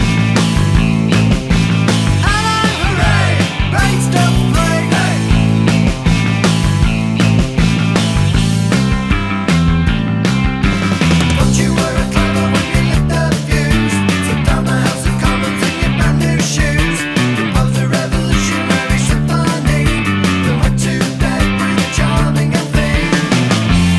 Minus Lead Guitar Pop (1980s) 3:58 Buy £1.50